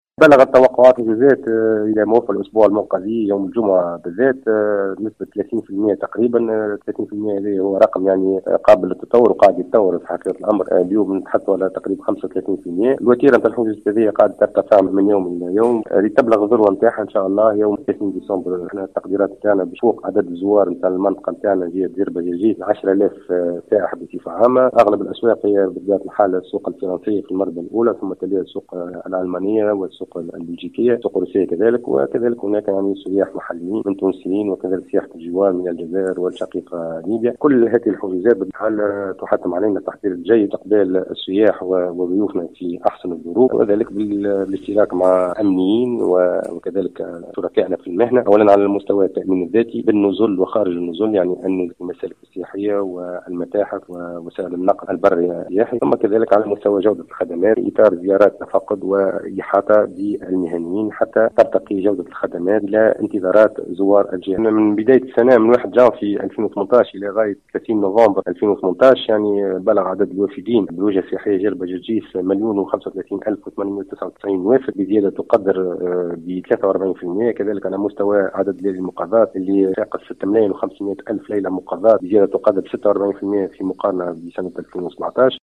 أكد هشام محواشي المندوب الجهوي للسياحة في مدنين في تصريح لمراسلة الجوهرة "اف ام" أن التوقعات للحجوزات بمناسبة رأس السنة بلغت إلى موفى الأسبوع المنقضي 30 بالمائة وهو رقم قابل للتطور .